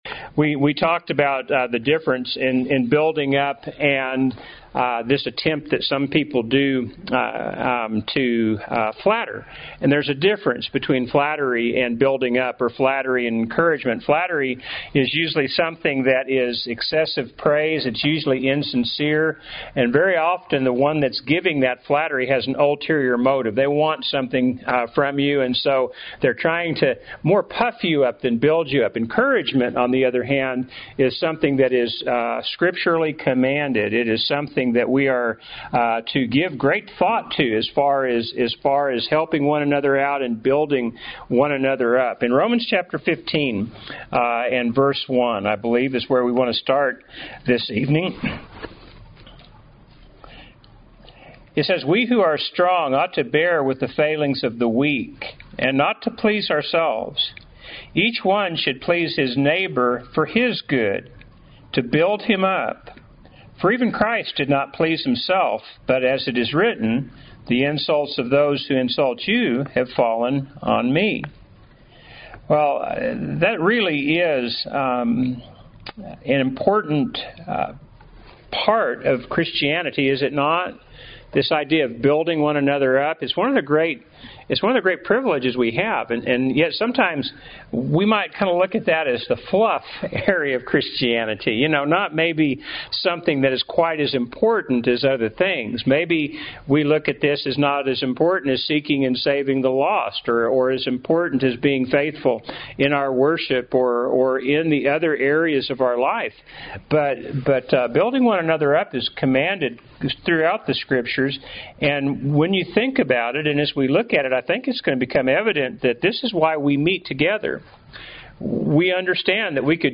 Happy Church of Christ Listen to Sermons